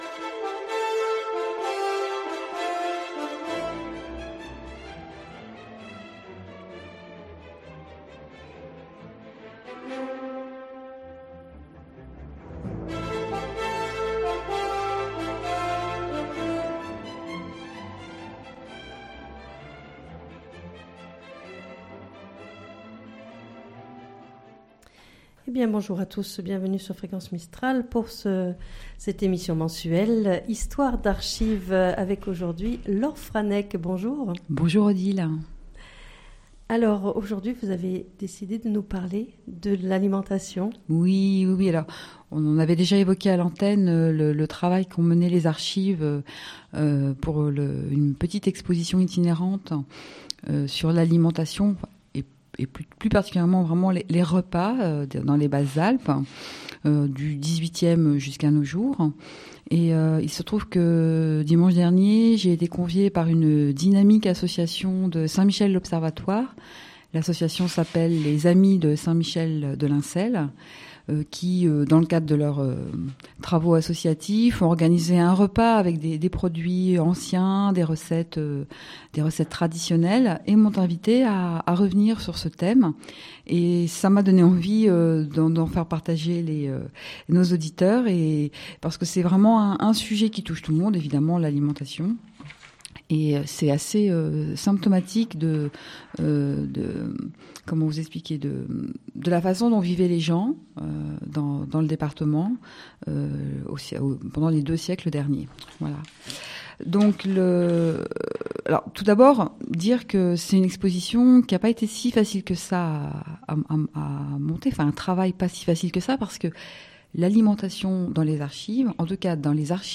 Une émission mensuelle chaque 3ème jeudi du mois de 9h15 à 10h, proposée, préparée et animée par